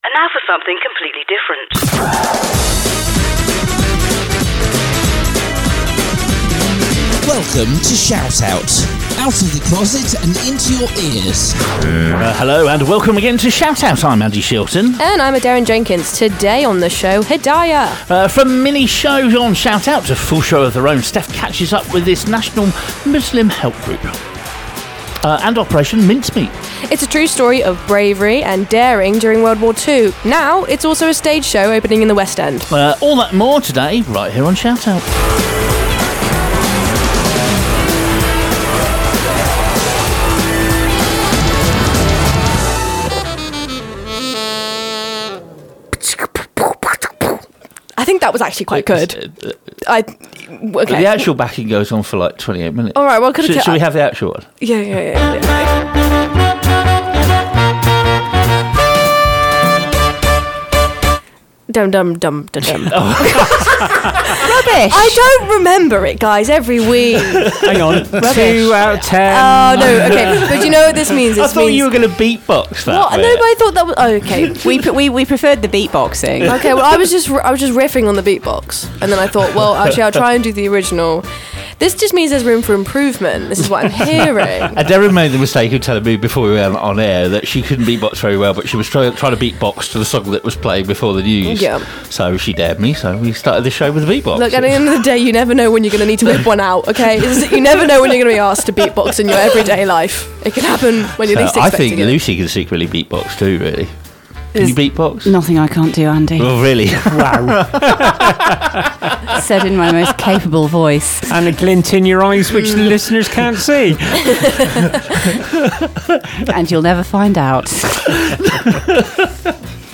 Broadcasting a brand new show every week, ShoutOut is a magazine show aimed at the LGBT community and their friends. We cover everything from serious to the stupid with live guests, events and news listing and special features.